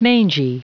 Prononciation du mot mangy en anglais (fichier audio)
Prononciation du mot : mangy